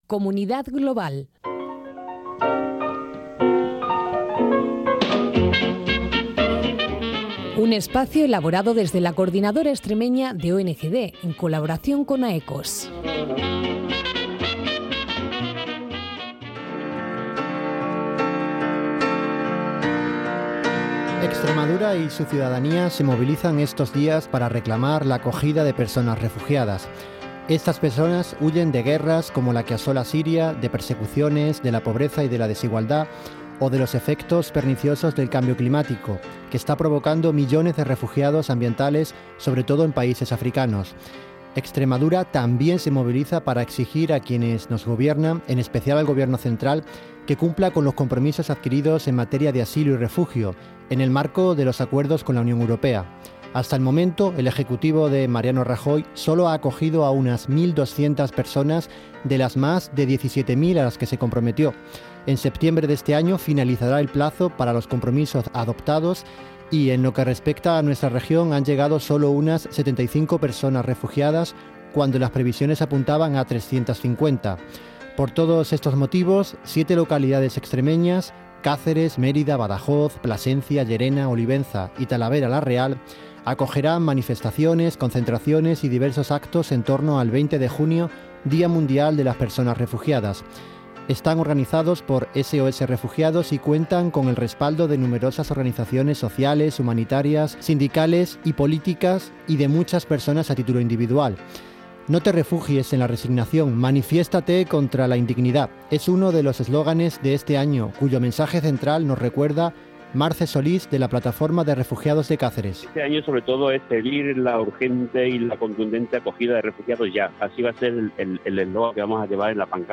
Entrevista en Canal Extremadura-Acciones en el DIA DE LAS PERSONAS y Caravana a Melilla.